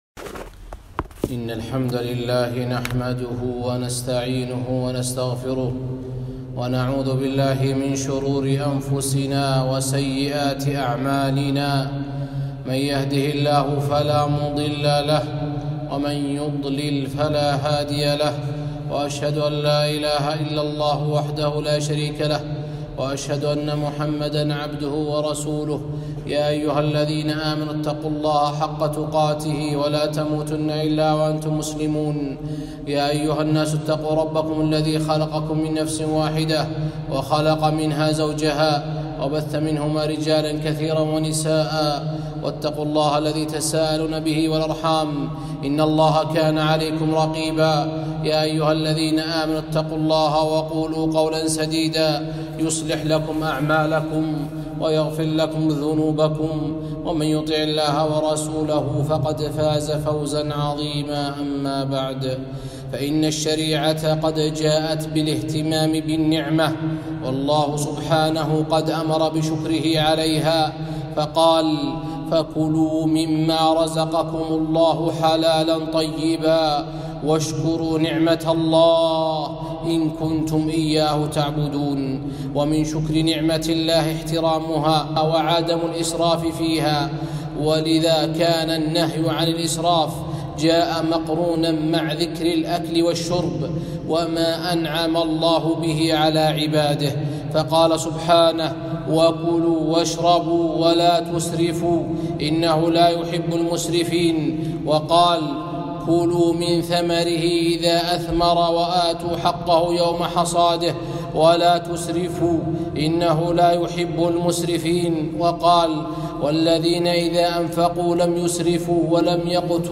خطبة - من احترام النِّعم وشكرها - دروس الكويت